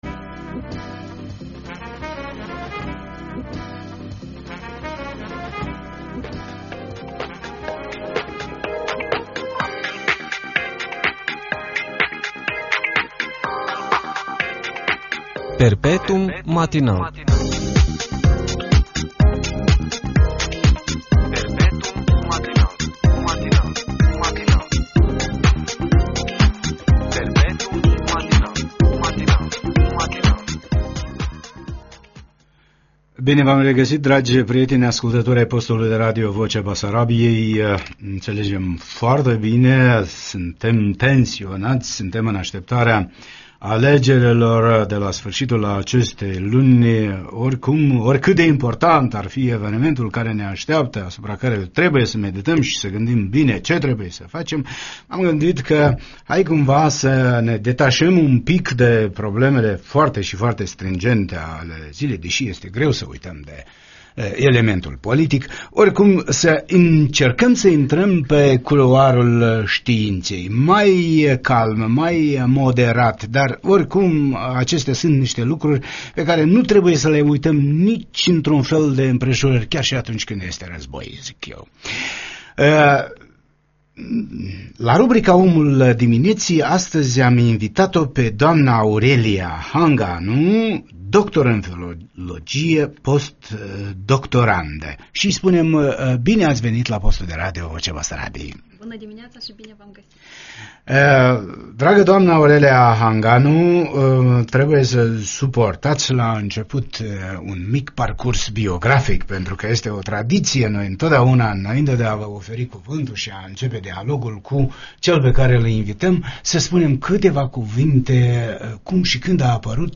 despre preocupările actuale din cadrul Institutului de Filologie etc. Va propunem întreg dialogul în format audio.